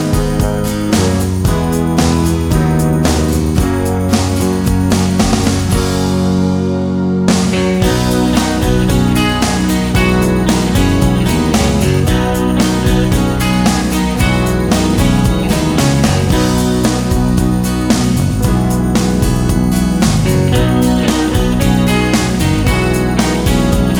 for duet Easy Listening 3:27 Buy £1.50